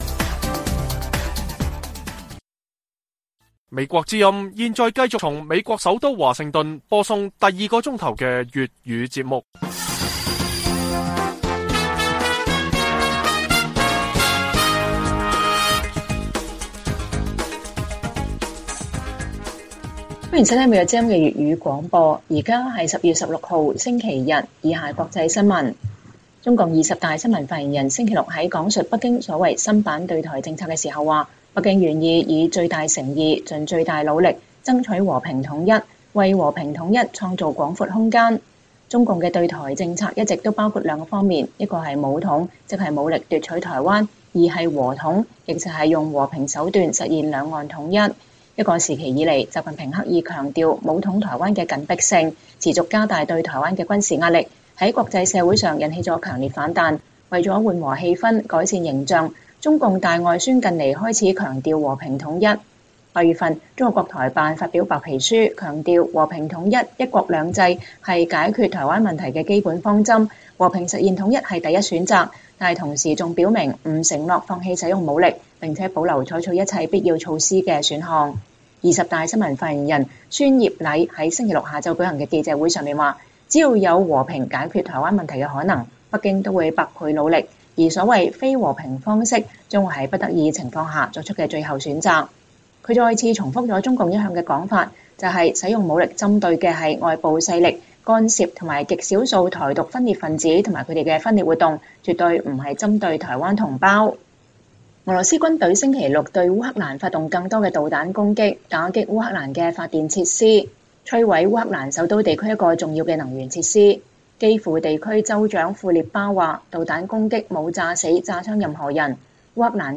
粵語新聞 晚上10-11點： 中共二十大前重申對台政策 舊瓶裝新酒？新瓶裝舊酒？